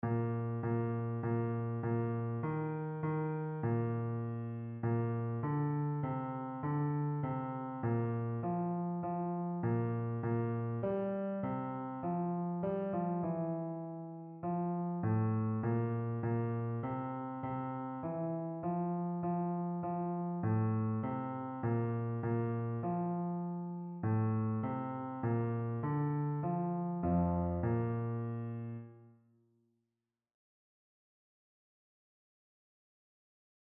Einzelstimmen (Unisono)
• Bass [MP3] 528 KB